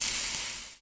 sizzle.ogg